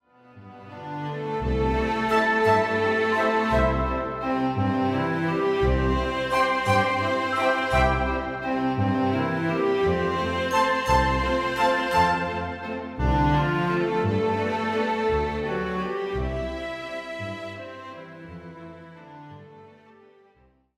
Walzer im barocken Saal
nordkirchen-making-off-walzer_mixdown.mp3